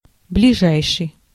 Ääntäminen
US : IPA : [ɪ.ˈmi.di.ɪt]